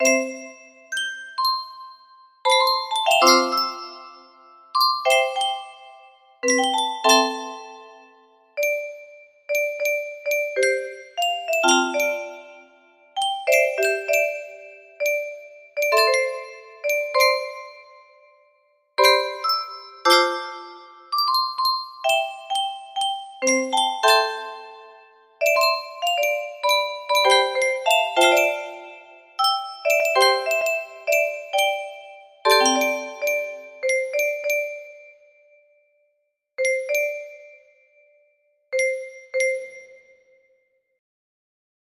Hey! It looks like this melody can be played offline on a 20 note paper strip music box!